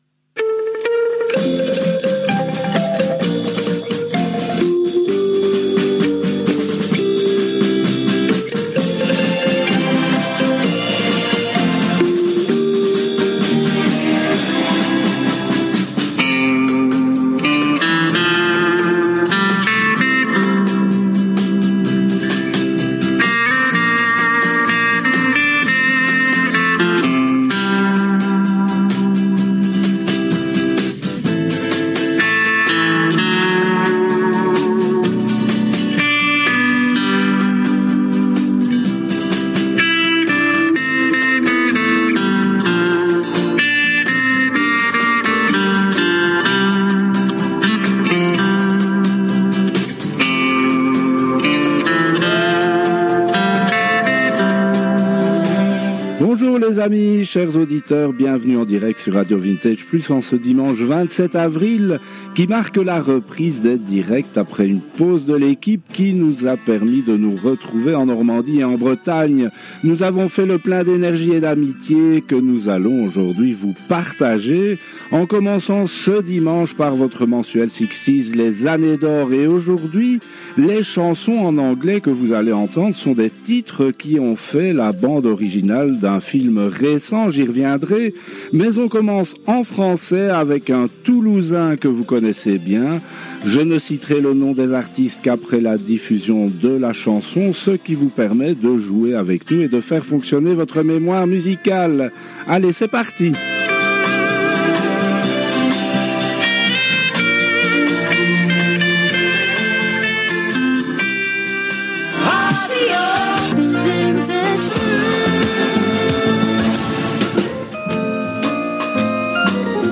Les Années d’Or sur RADIO VINTAGE PLUS, c’est une émission mensuelle entièrement consacrée aux SIXTIES. Cette 44ème édition a été diffusée le dimanche 27 avril 2025 à 10 heures en direct des studios de RV+ en BELGIQUE